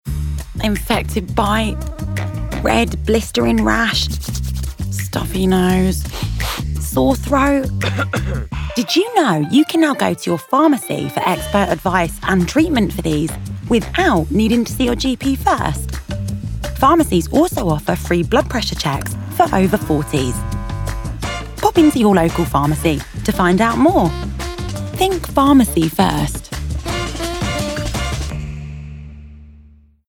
NHS Pharmacy First Spotify Radio Ad